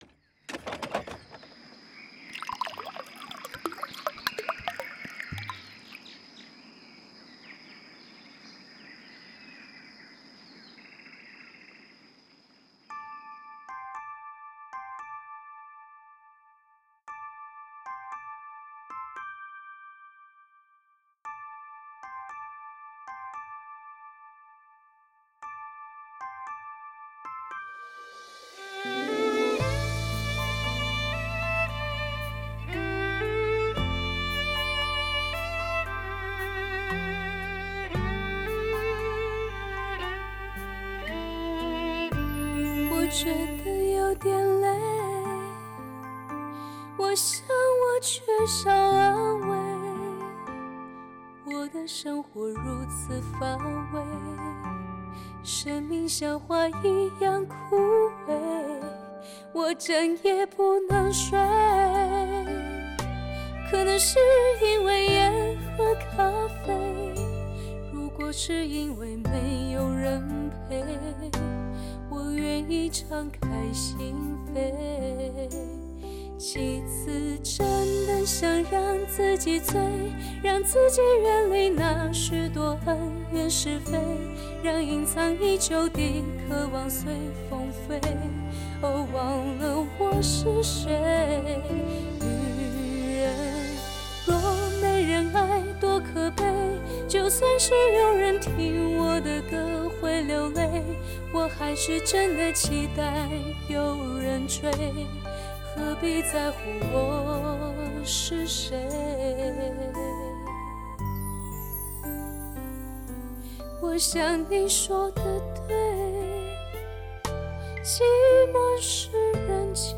赋予歌曲全新生命的灵魂演唱，
采用最新分离式DTS6.1顶级编码器处理，
配合专业精湛的录音，
带来超乎想象震撼性的环绕声体验。